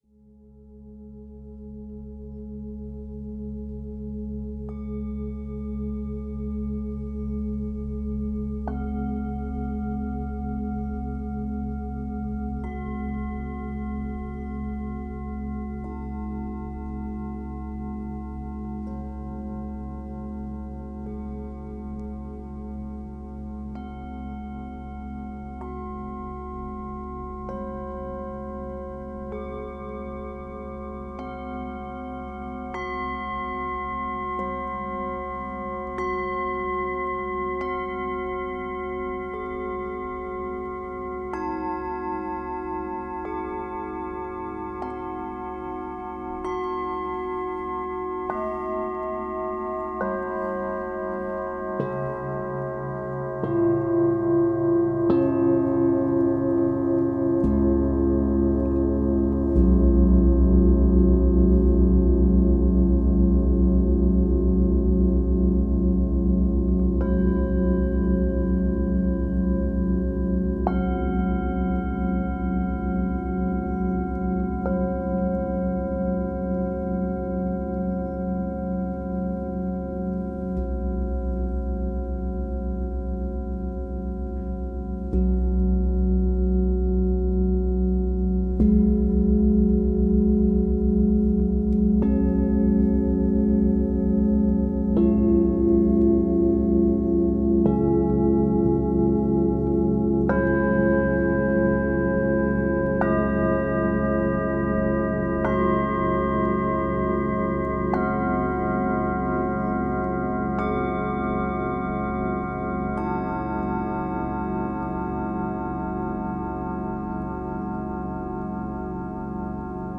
spielt alte Planetenklangschalen zum Entspannen und Träumen.